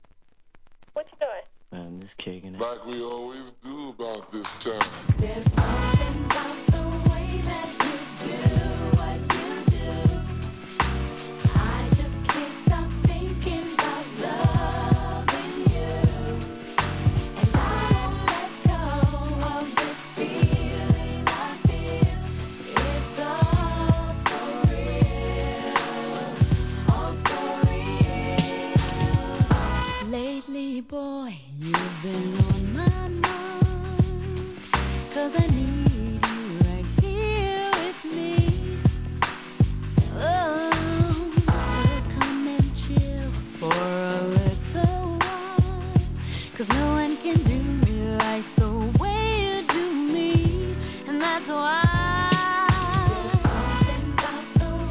'90s R&B CLASSIC